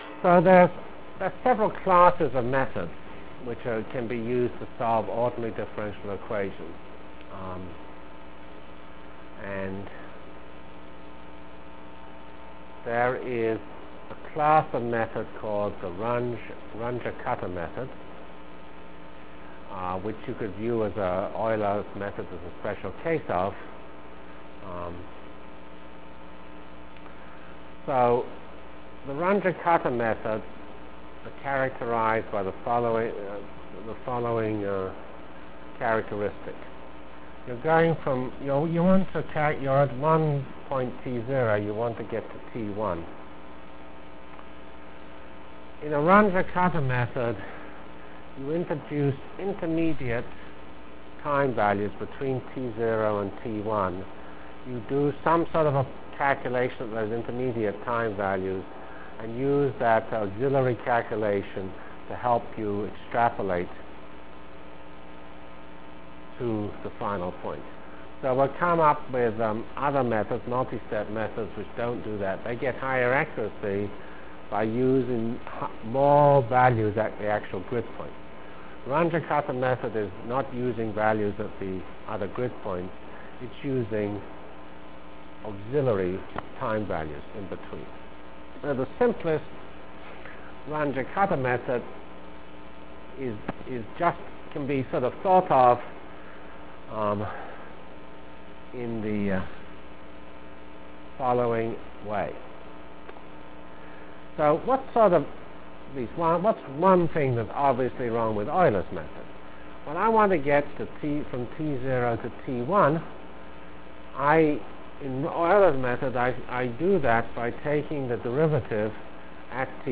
From CPS615-Discussion of Ordinary Differential Equations and Start of Parallel N-Body Algorithm Delivered Lectures of CPS615 Basic Simulation Track for Computational Science -- 10 October 96. by Geoffrey C. Fox *